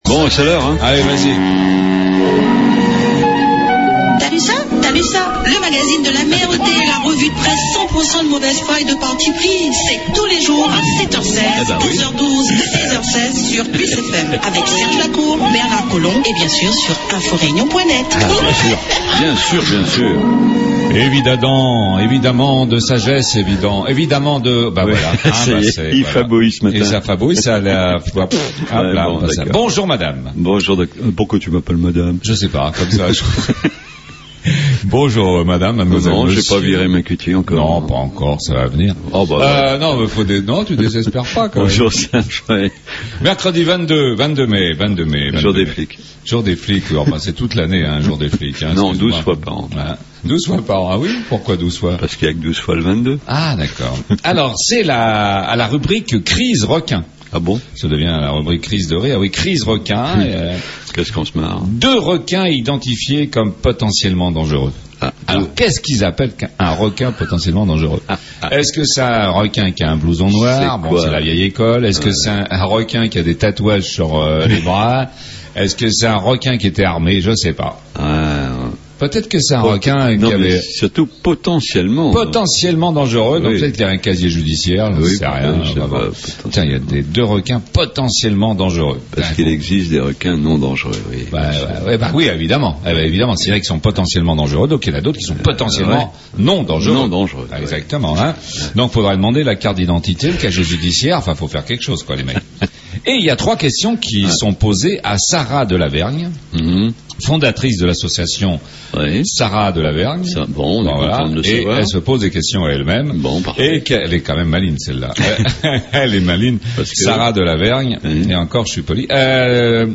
" T'as lu ça ? " , le magazine de l'amer, revue de presse